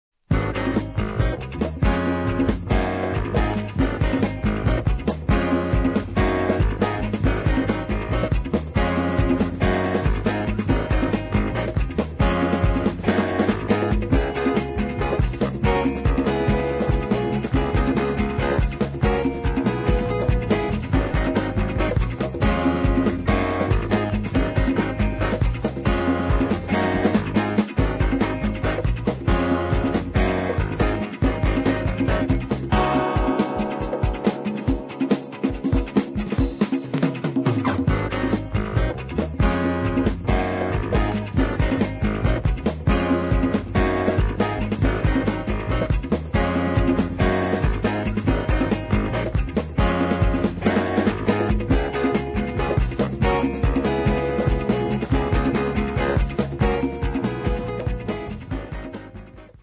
as this wah and horns track demonstrates.